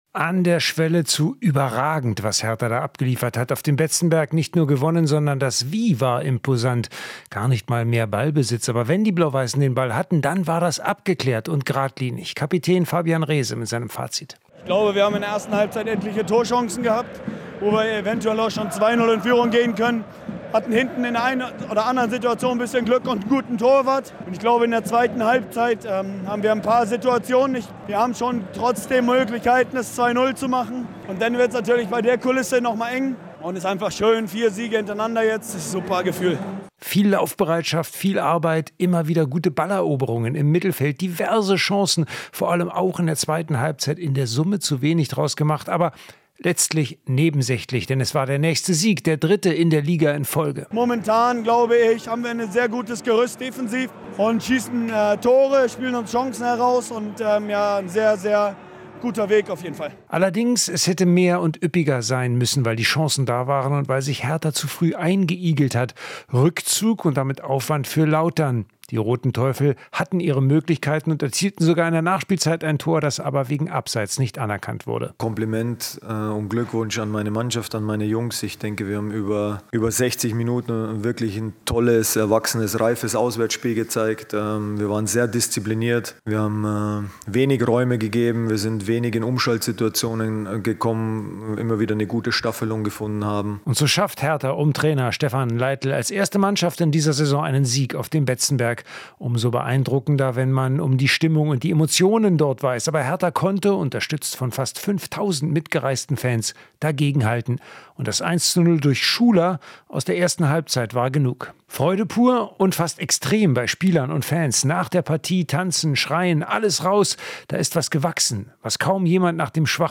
In Interviews und Reportagen blicken wir auf den Sport in der Region und in der Welt.